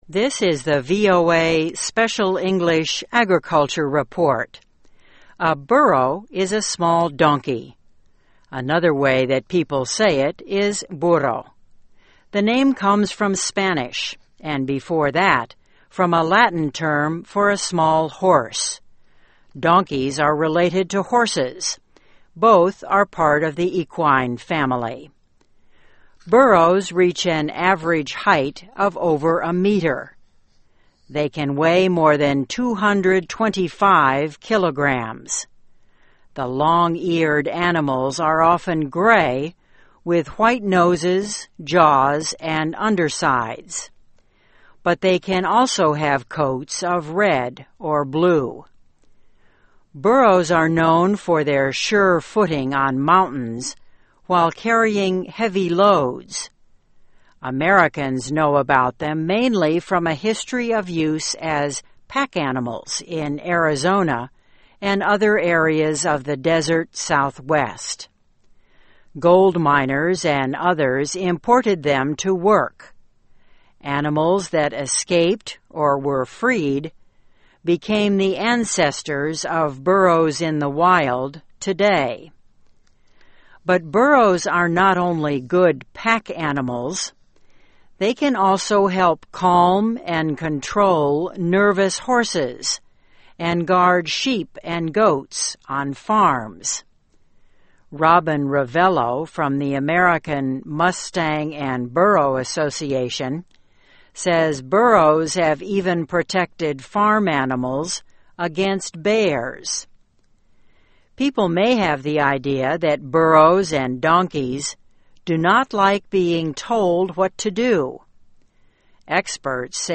Aquí se  incluyen algunos ejercicios de comprensión auditiva en inglés americano.